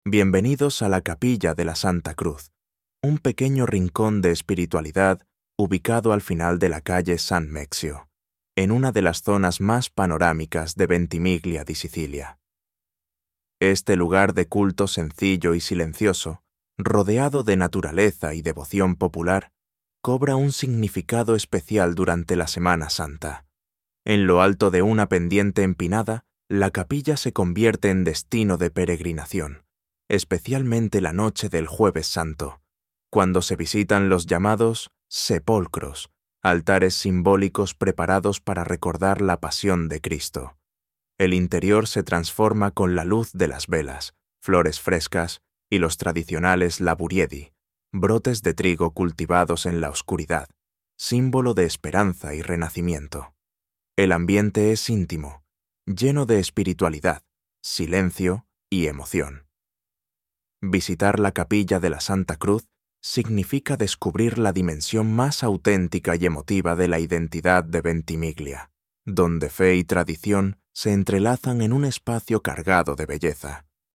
Audio Guida